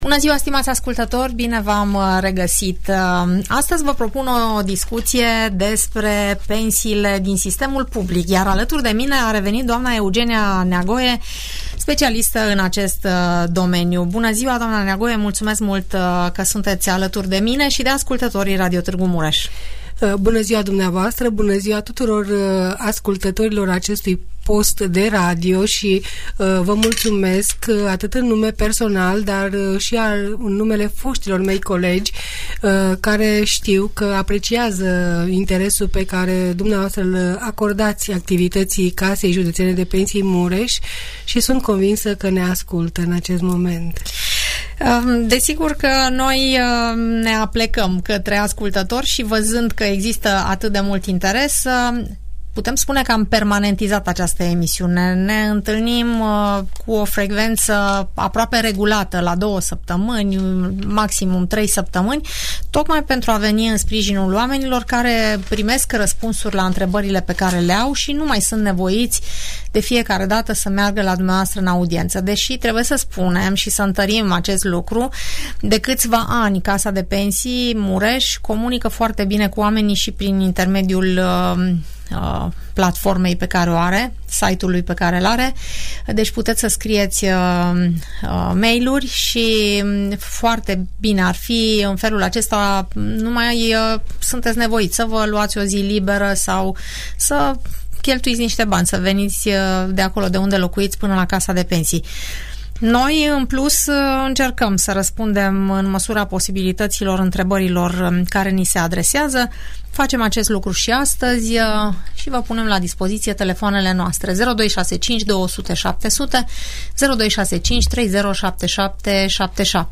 Audiență radio cu întrebări și răspunsuri despre toate tipurile de pensii, în emisiunea "Părerea ta" de la Radio Tg Mureș.